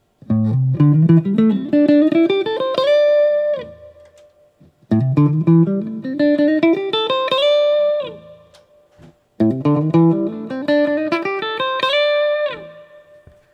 I did two things while recording these snippets: a chord progression and a quick pentatonic lick.
1986 X-500 Lick
I also find it interesting that the soundblock-equipped 1986 X-500 has a much tighter response when plugged in.